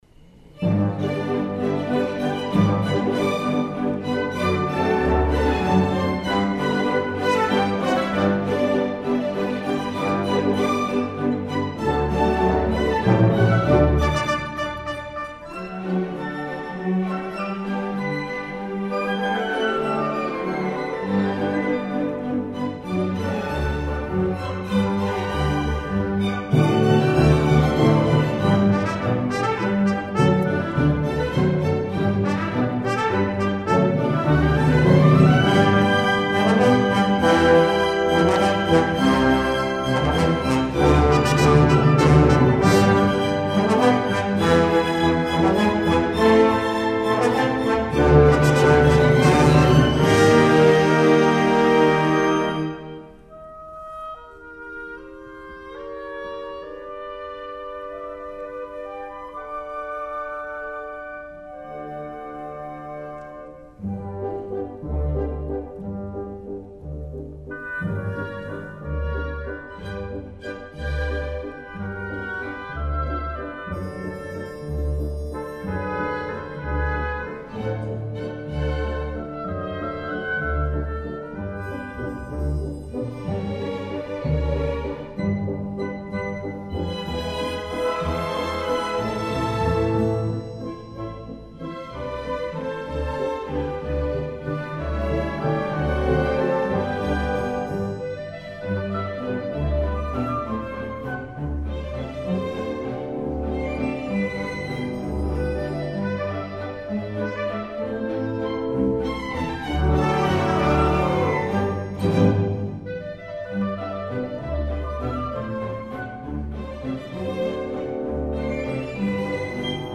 Chrysanthemes για Ορχήστρα (live)
Συμφωνική Ορχήστρα Φιλαρμονικής Εταιρείας Κέκρυρας